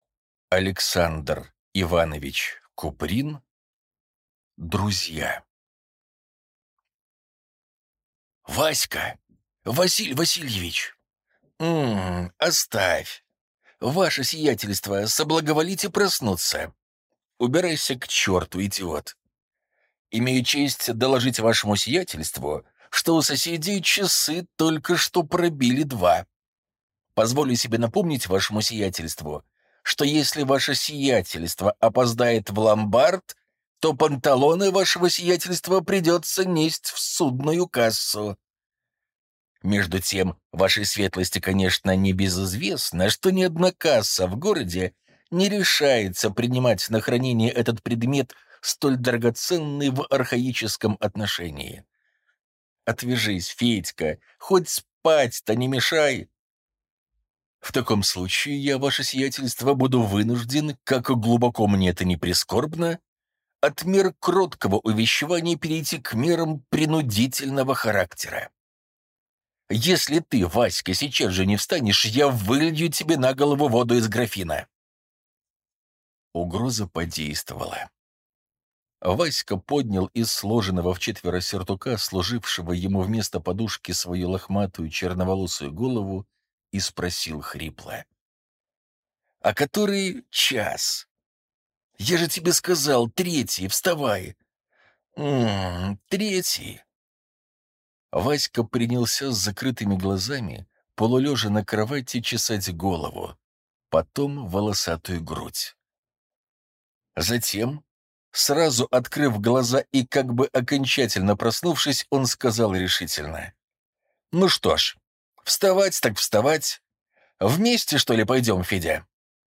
Аудиокнига Друзья | Библиотека аудиокниг